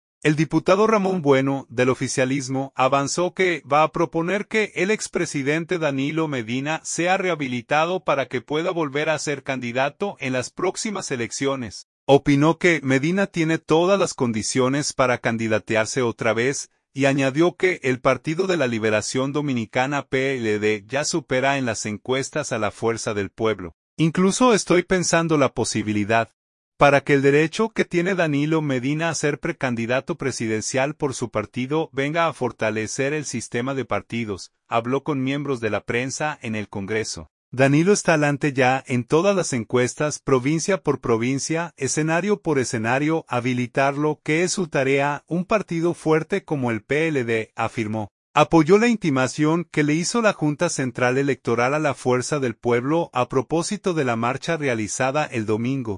Habló con miembros de la prensa, en el Congreso.